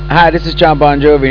GREETING FROM